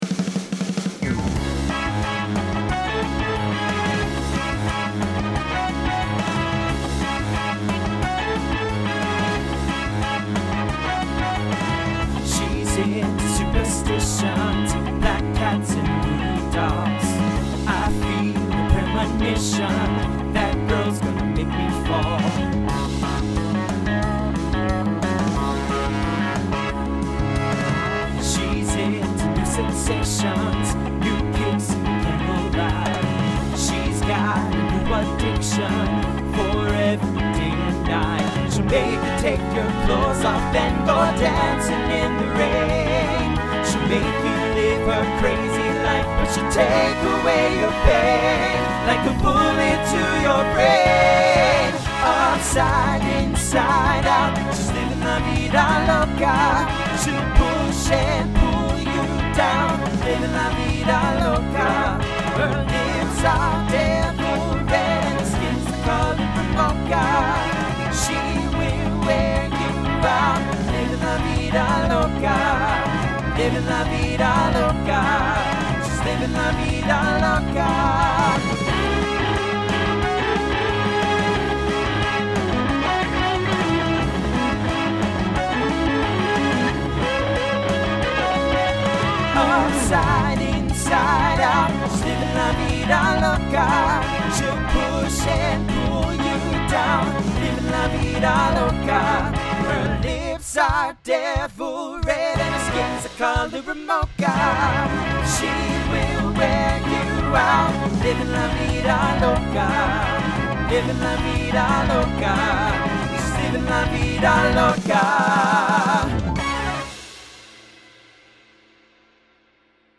Genre Latin , Rock
Transition Voicing TTB